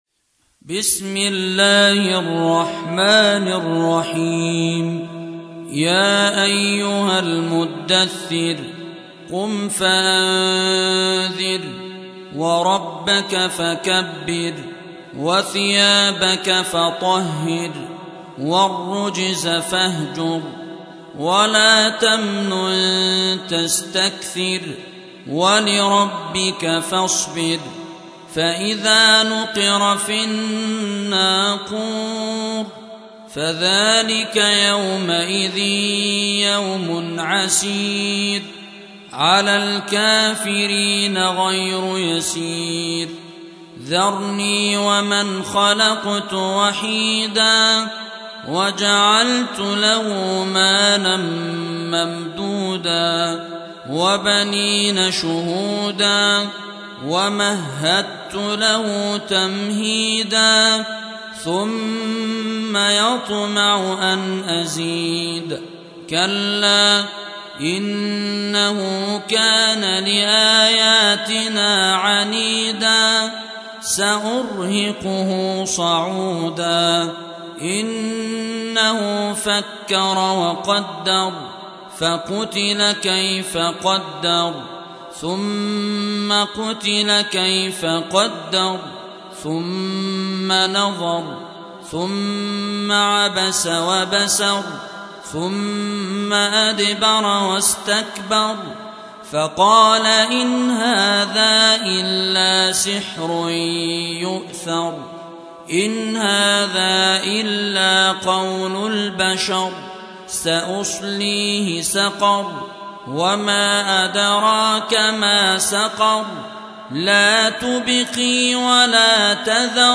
Surah Repeating تكرار السورة Download Surah حمّل السورة Reciting Murattalah Audio for 74. Surah Al-Muddaththir سورة المدّثر N.B *Surah Includes Al-Basmalah Reciters Sequents تتابع التلاوات Reciters Repeats تكرار التلاوات